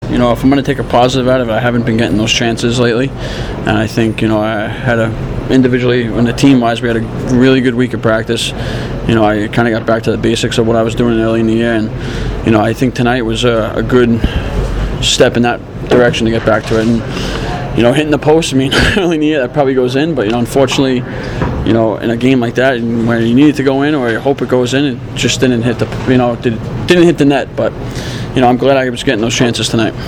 Being that last night was a concert night (and a loss) I zipped through post-game interviews.